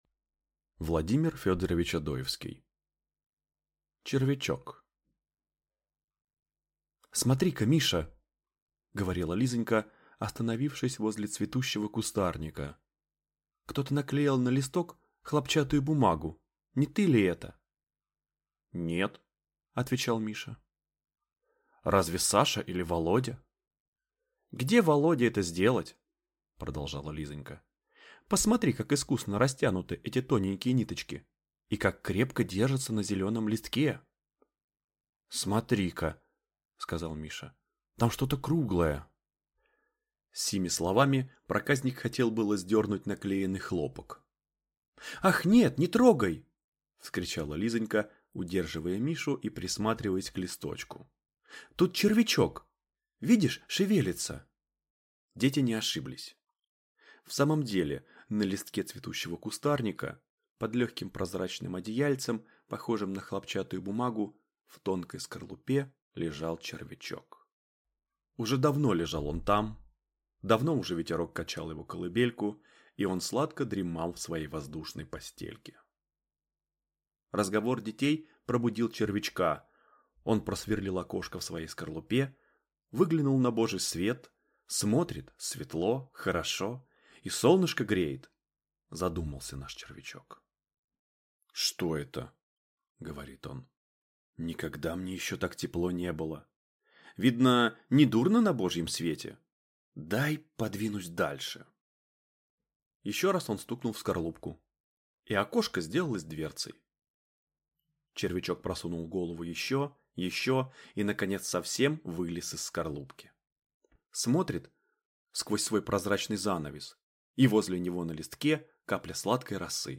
Аудиокнига Червячок | Библиотека аудиокниг